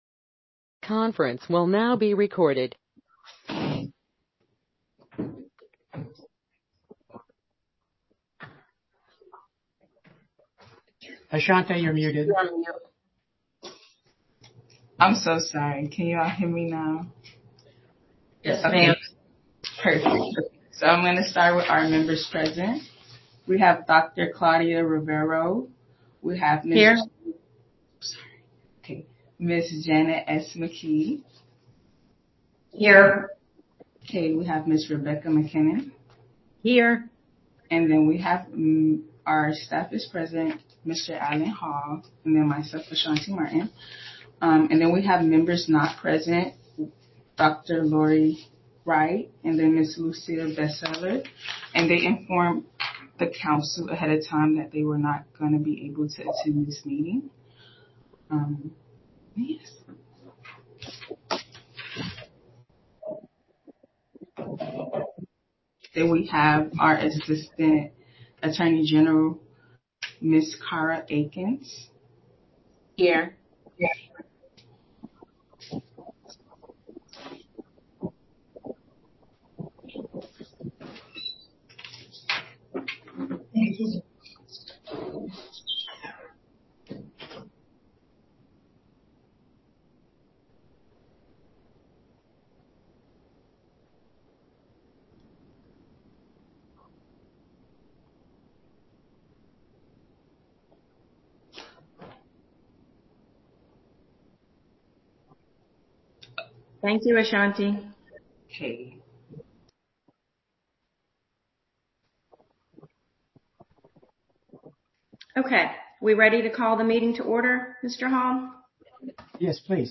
The council conducts most of their meeting through telephone conference call.